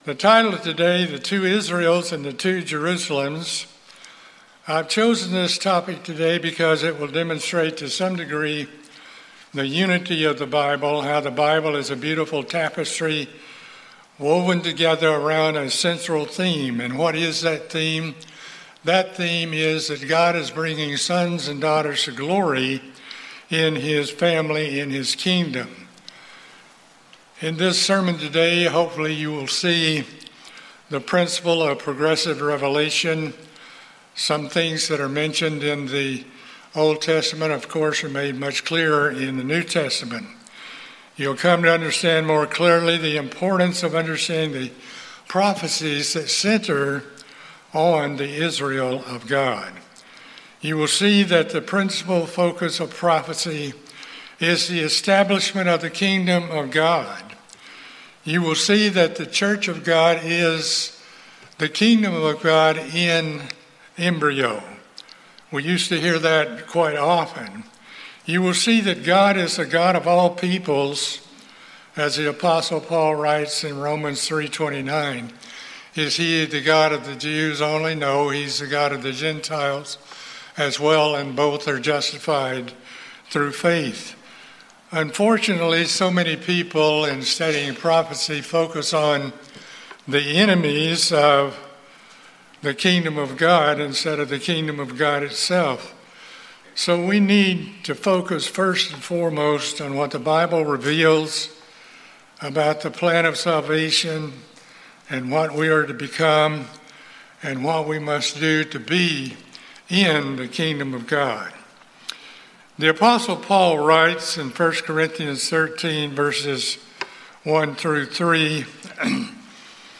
This sermon demonstrates that physical Israel and physical Jerusalem also have spiritual dimensions that are being fulfilled by the Church of God, the Israel of God.